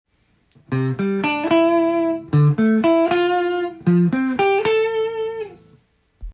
規則性があり５弦４弦を１一音ずつ、２弦を２つずつ
順番に弾くというパターンです。